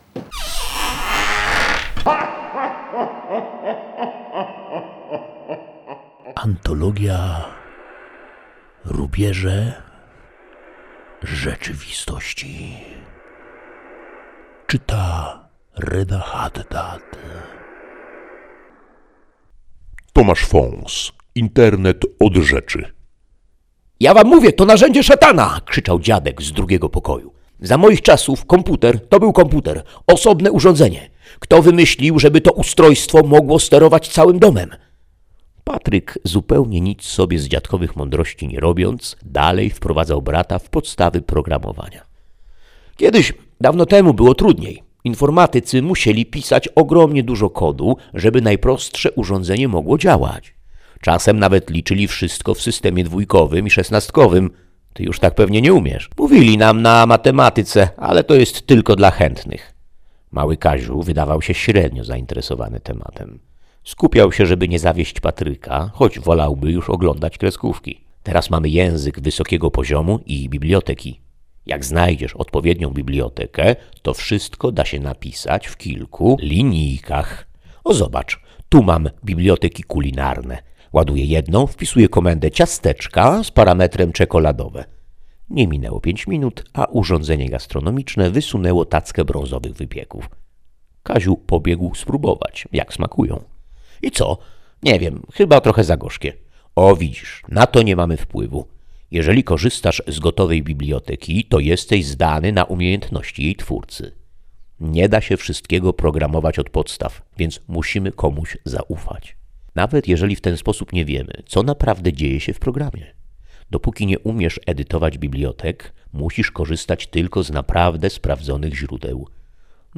Fragment opowiadania: